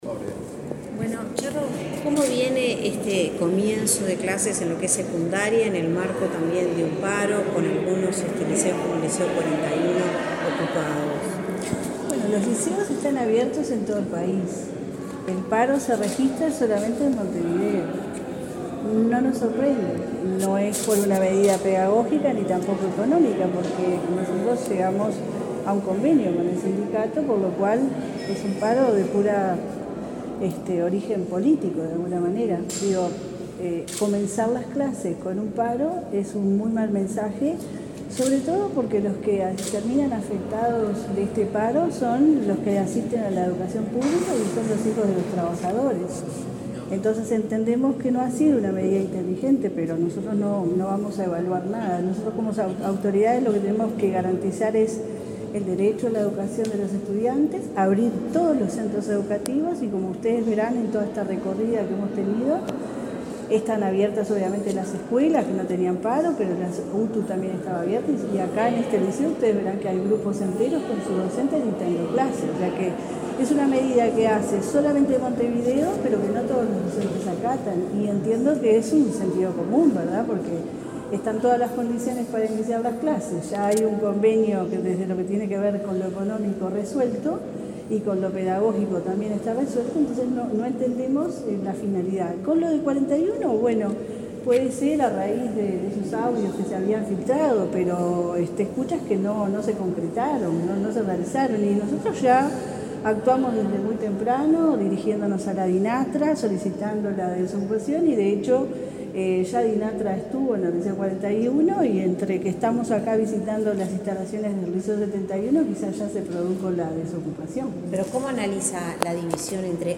Declaraciones de la directora de Secundaria, Jenifer Cherro
La directora de Secundaria, Jenifer Cherro, dialogó con la prensa, luego de recorrer varios centros educativos este lunes 6, por el inicio del año